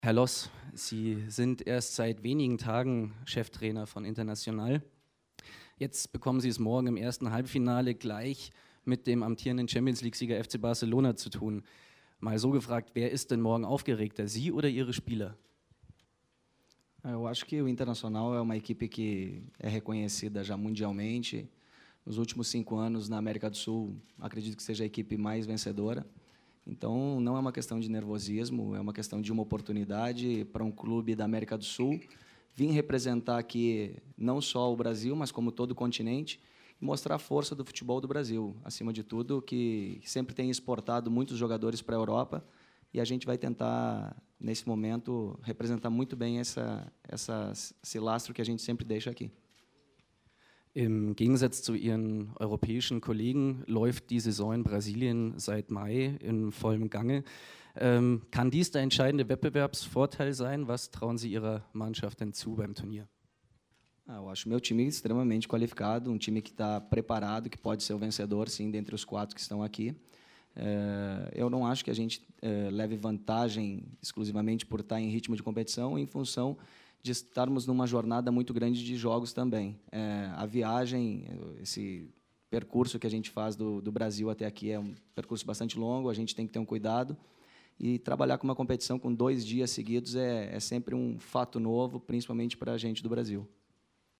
(nicht overvoiced)